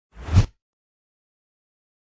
دانلود آهنگ دعوا 1 از افکت صوتی انسان و موجودات زنده
جلوه های صوتی
دانلود صدای دعوا 1 از ساعد نیوز با لینک مستقیم و کیفیت بالا